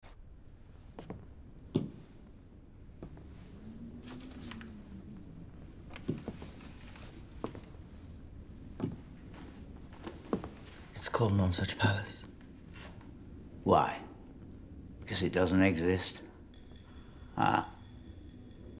Henry has entered the ‘crazy drawing’ phase of his grief isolation program, it’s still always dark, and every iteration of this scene the ‘low wind on stone walls’ sound effect does an awful lot of work.
wind-effect.mp3